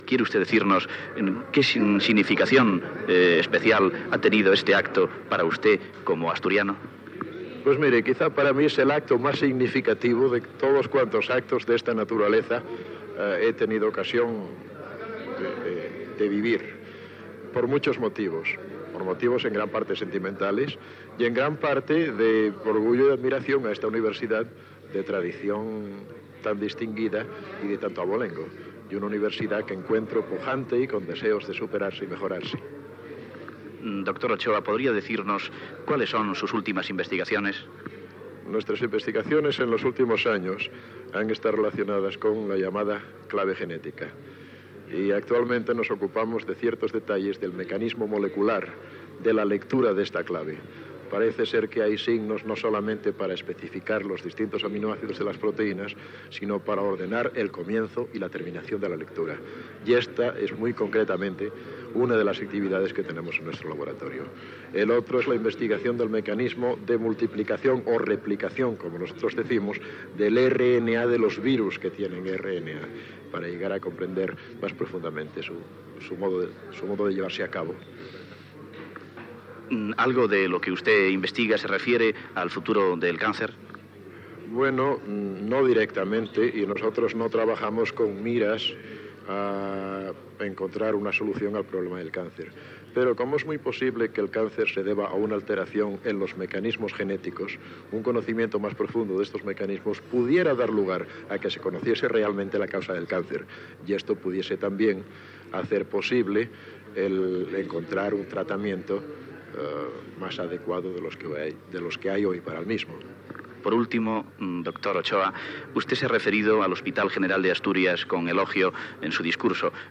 Entrevista al doctor Severo Ochoa el dia que el van investir doctor honoris causa a la Universidad de Oviedo
Informatiu
Extret del programa "El sonido de la historia", emès per Radio 5 Todo Noticias el 22 de setembre de 2012